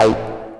tekTTE63006acid-A.wav